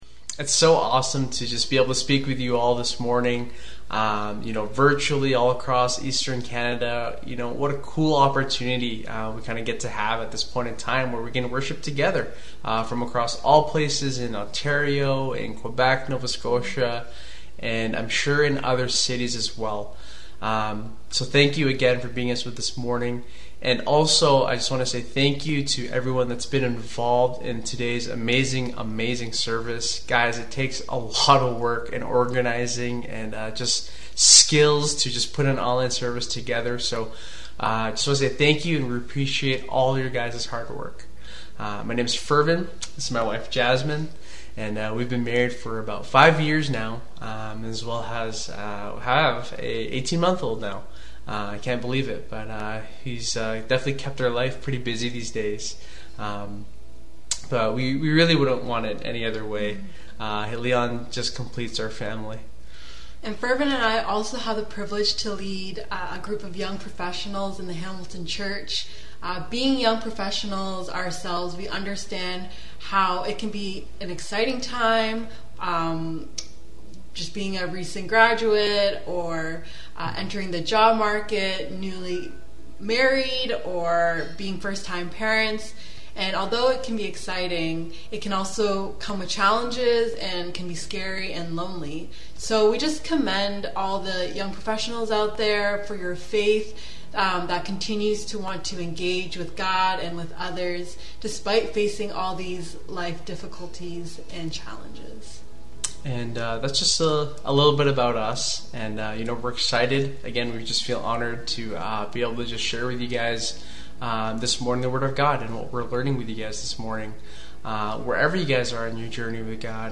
God’s truth is such a powerful way that helps us be set free from the lies we tend to believe. Come join us for our special monthly Eastern Canadian Worship Service as we hear a very engaging message and relevant sharing by many young Christians from the Next Generation on this topic!